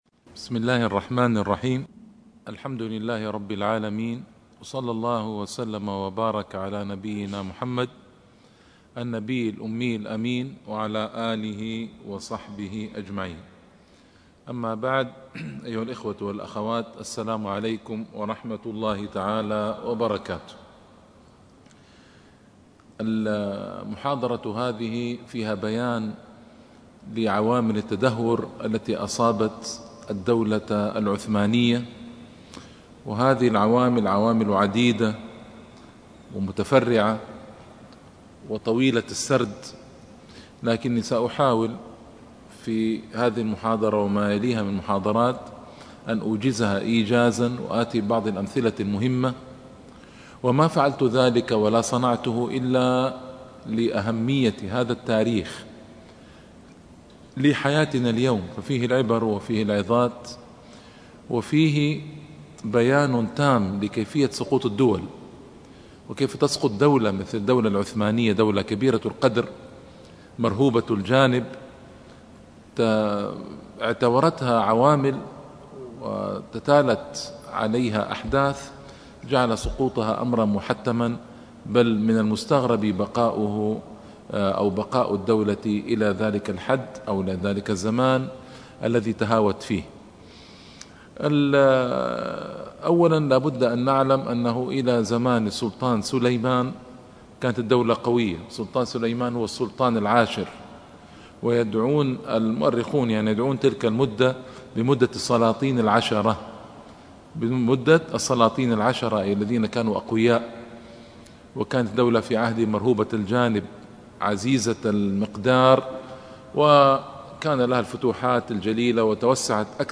الحضارة العثمانية عوامل النهوض وأسباب السقوط.. الدرس الأول